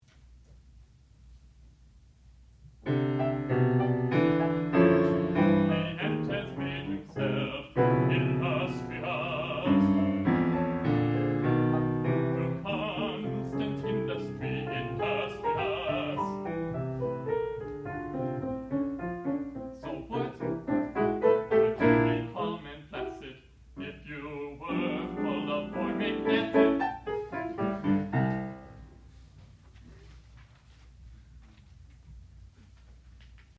sound recording-musical
classical music
soprano
piano
baritone